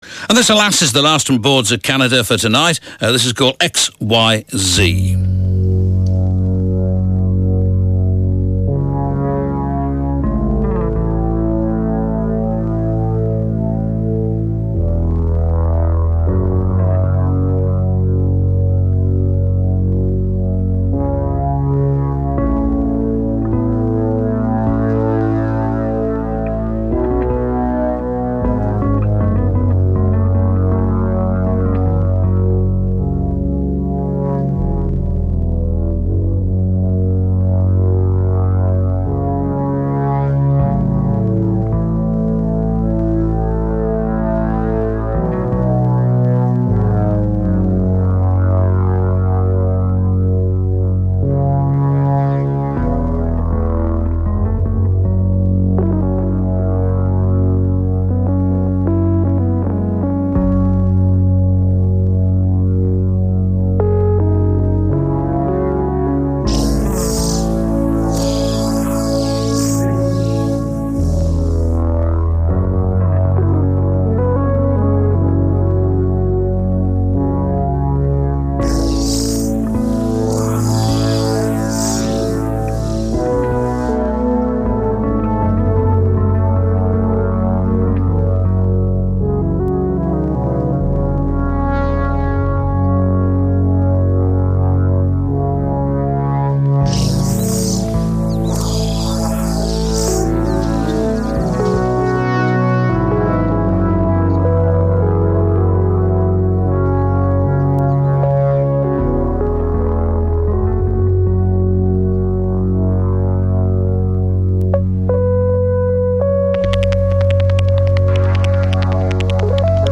London, Uk venue Radio 1